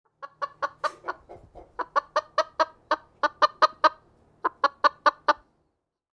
Chickens In A Barn 2 Botão de Som